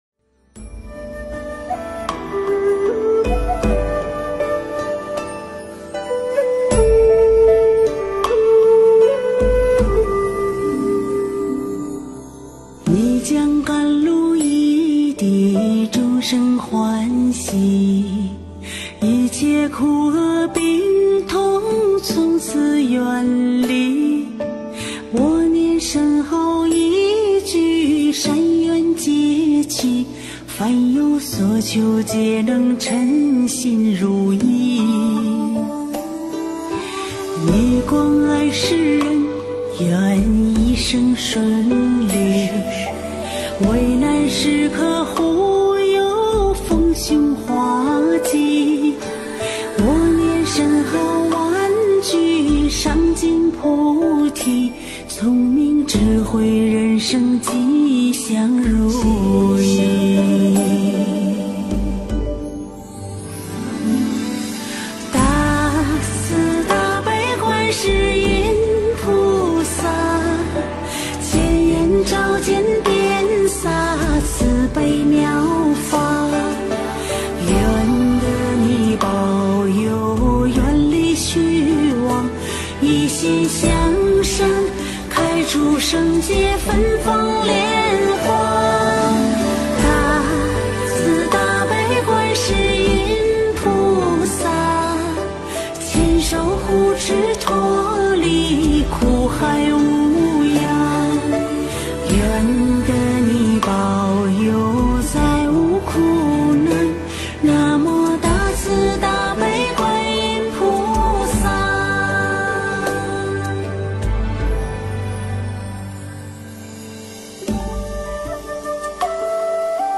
音频：温哥华观音堂素食分享花絮！2023年元宵节